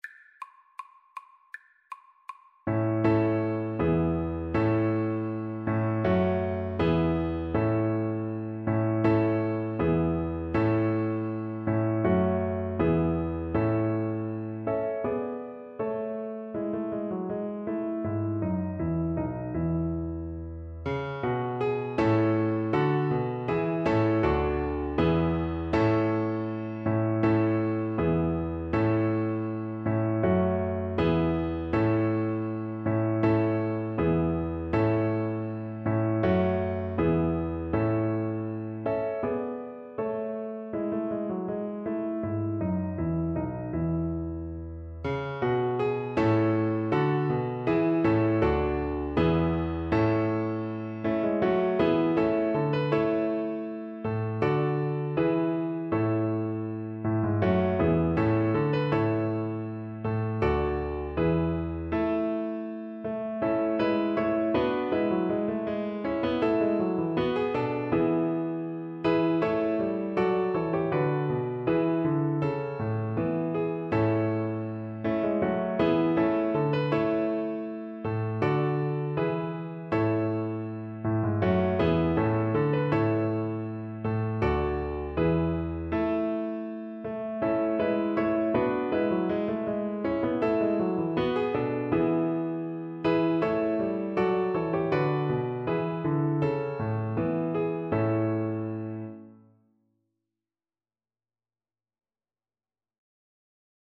2/2 (View more 2/2 Music)
A major (Sounding Pitch) (View more A major Music for Viola )
Viola  (View more Easy Viola Music)
Classical (View more Classical Viola Music)